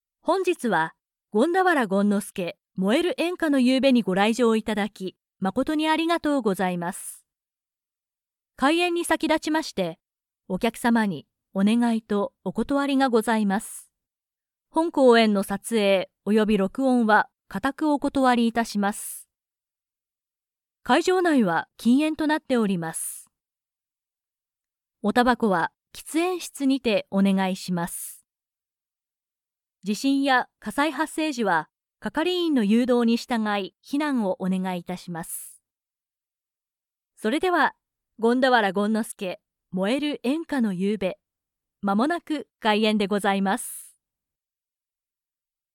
司仪主持-开幕致辞